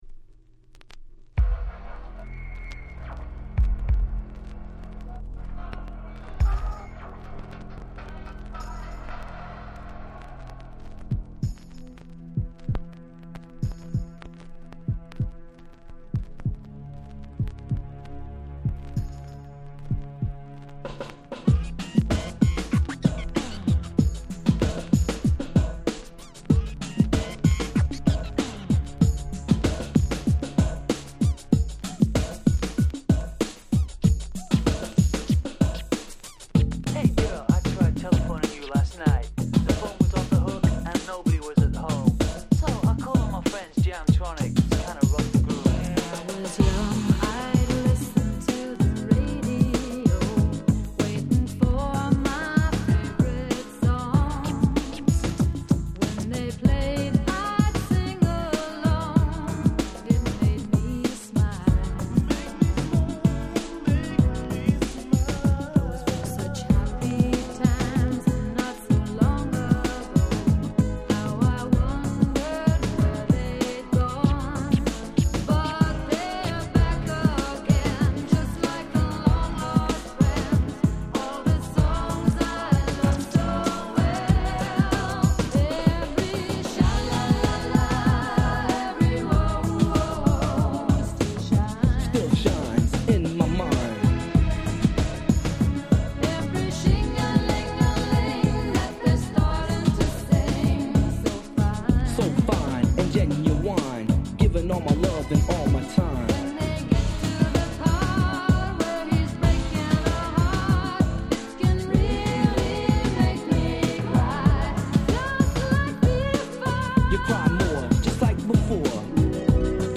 今回はUK R&B, Ground Beat特集！